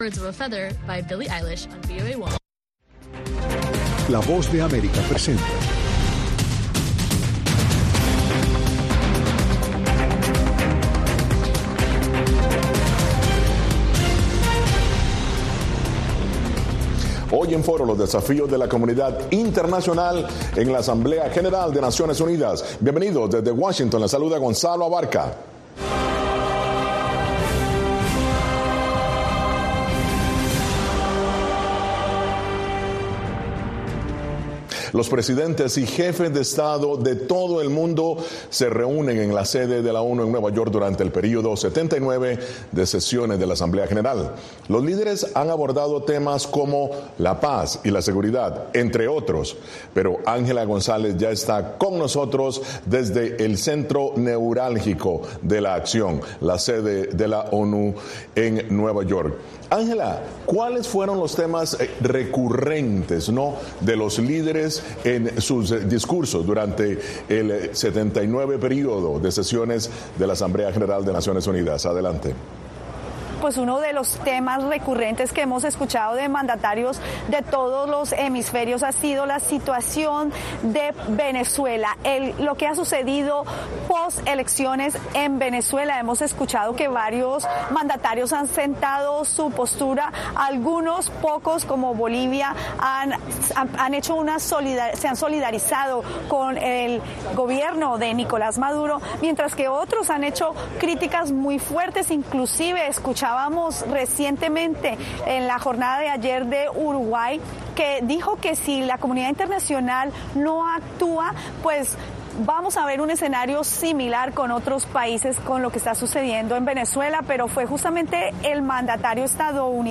En esta edición de Foro presentamos entrevistas con el Subsecretario de Estado de Estados Unidos para el Hemisferio Occidental, Brian A. Nichols y la canciller de México, Alicia Bárcena durante 79 Asamblea General de la ONU que concentró a líderes mundiales para abordar temas diversos.